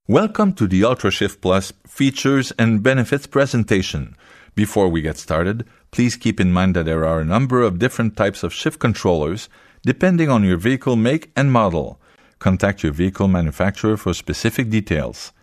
French-Canadian, Male, 30s-50s